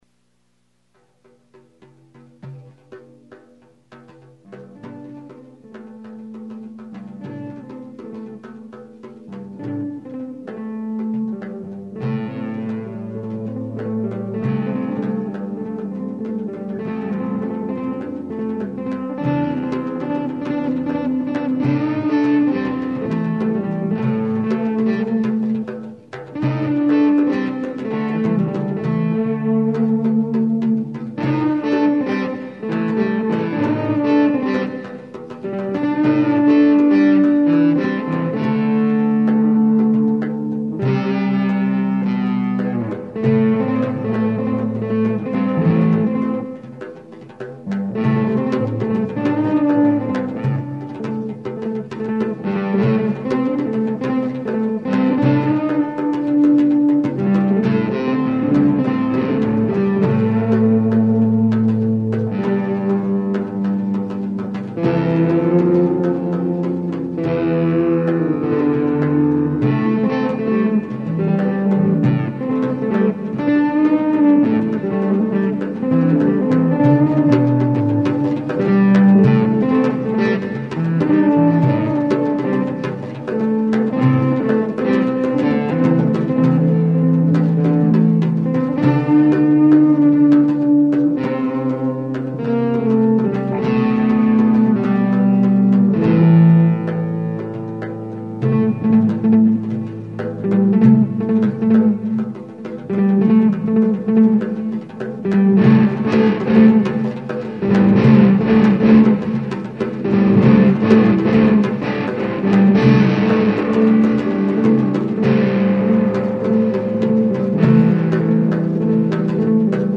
Improvisation w / drum loop